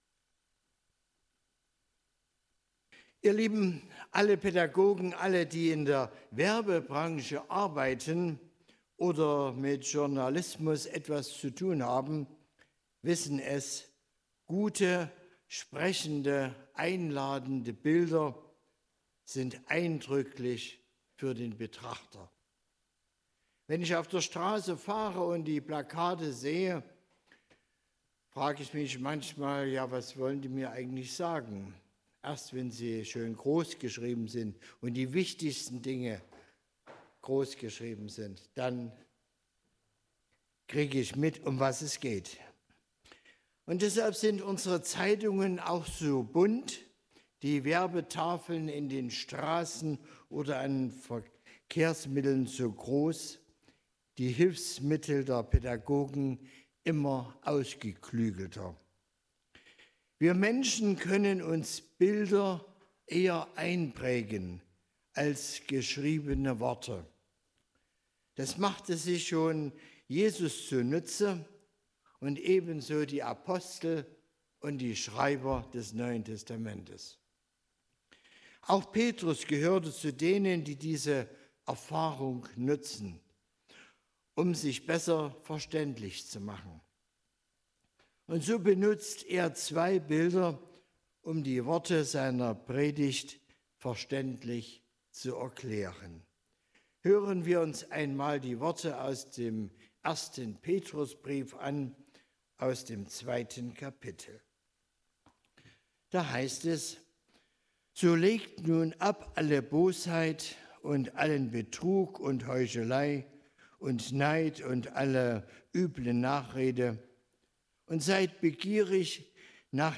Petrus 2, 1-10 Gottesdienstart: Predigtgottesdienst Obercrinitz In der Werbung werden gern gute, einprägende Bilder verwendet.